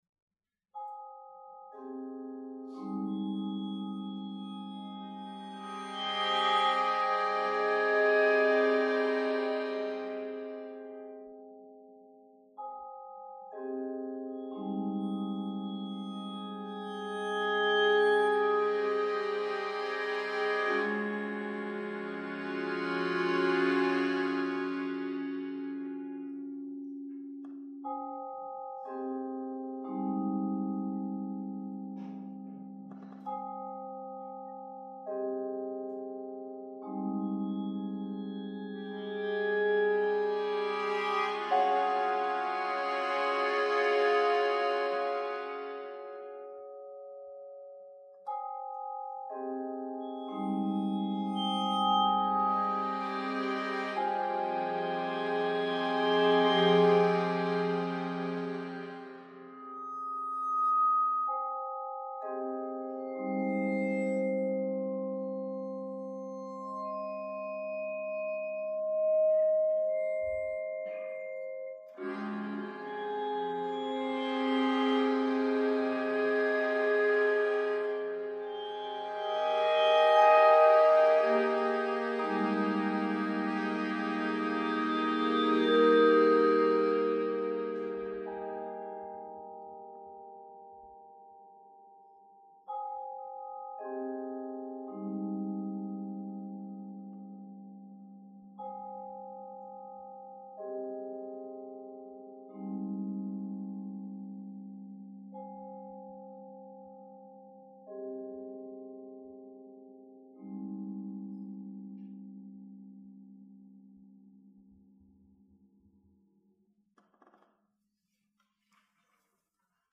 Voicing: 7 - 9 Percussion